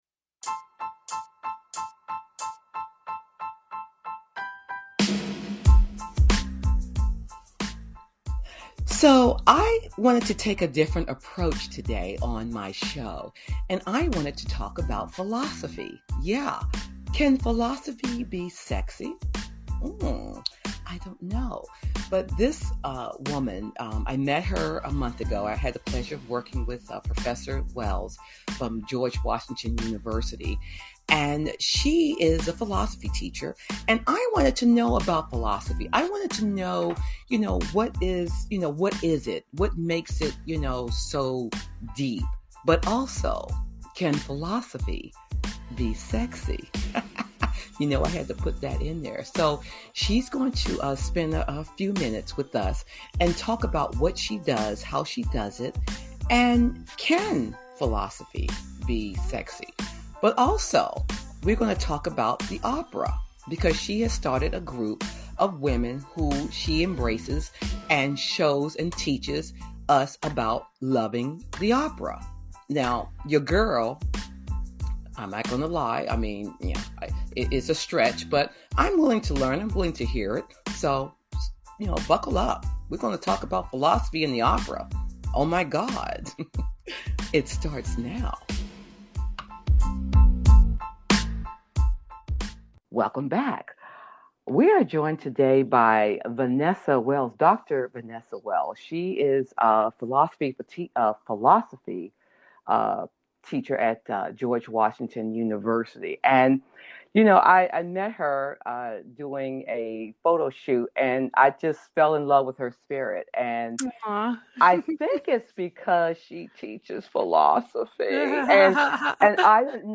Together, they challenge old narratives, embrace the wisdom that comes with experience, and invite listeners to lean fully into the chapter they are living today. With warmth, curiosity, and a touch of humor, this episode reminds us that deep thinking isn’t just powerful — it can be liberating.
Talk Show